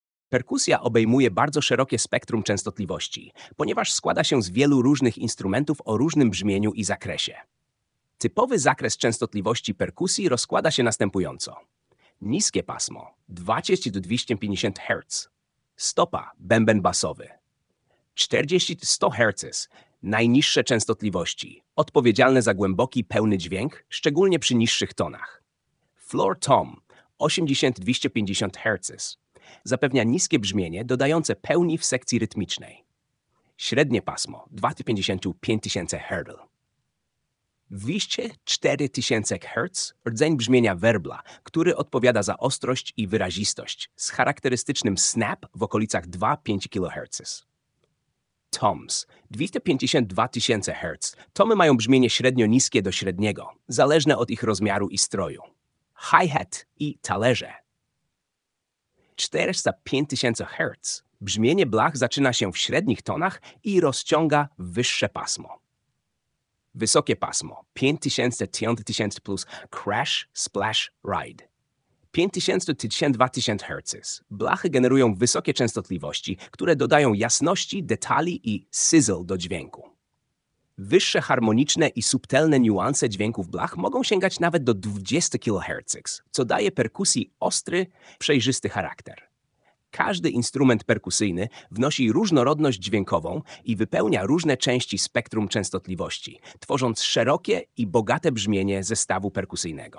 Perkusja pasmo akustyczne
Lektor
Perkusja-pasmo.mp3